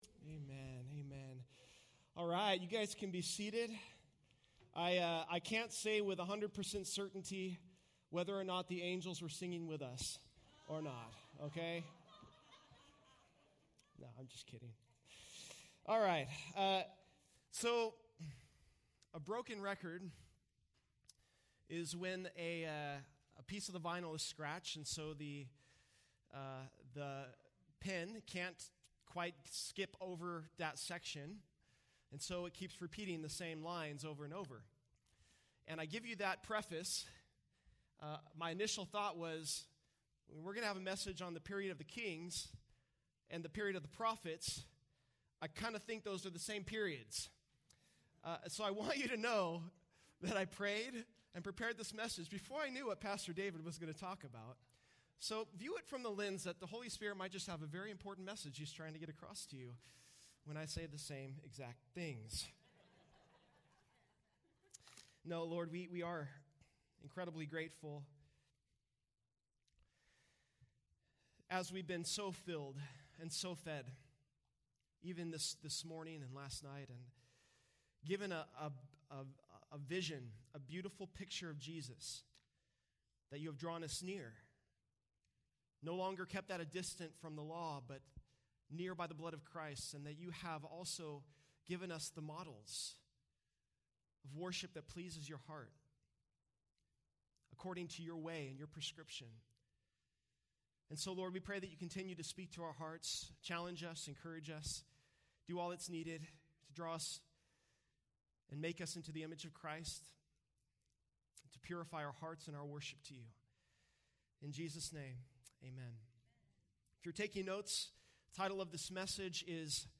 Home » Sermons » DSWC 2023 – Session 3